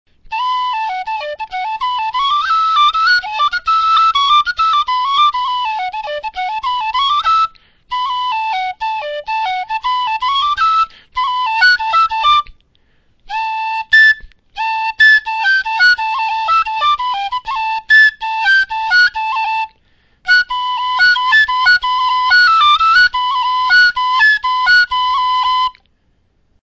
Using the tongue very softly, for separation rather than attack, and using the breath to make the higher notes stand out more.
This is my usual technique for such passages.